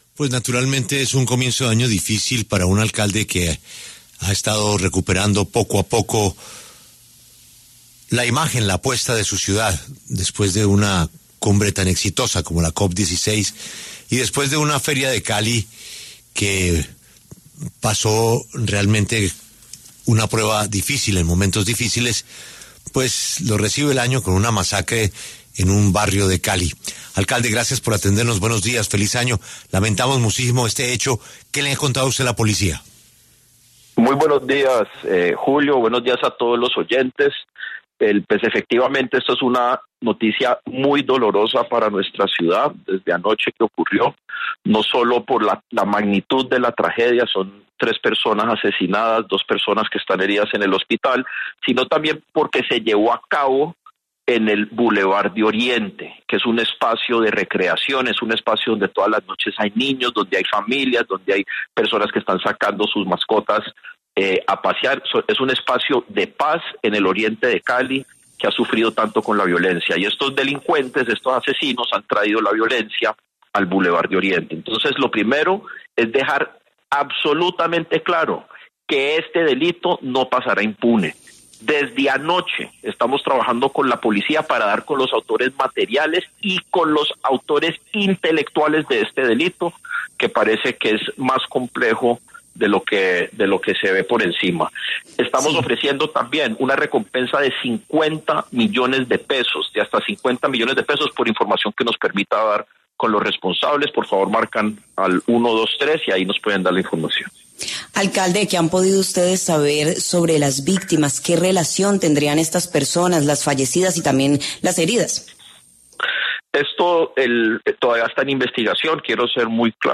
En entrevista con La W, el alcalde de Cali, Alejandro Eder, calificó el triple homicidio registrado en el Boulevard del Oriente, como “una tragedia” y aseguró que las autoridades están tomando medidas para esclarecer el crimen y reforzar la seguridad en este sector de la ciudad.